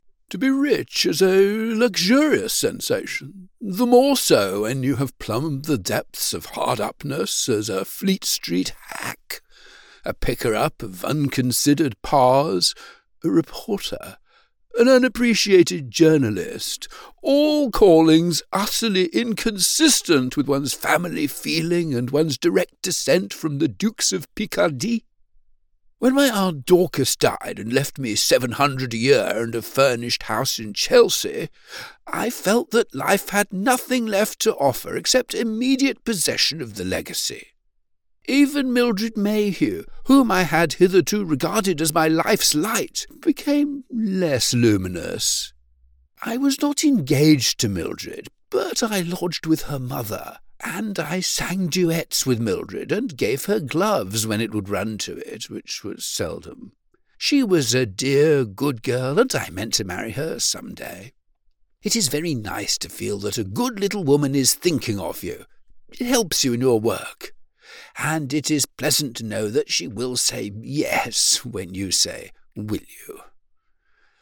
British Audiobook Narrator: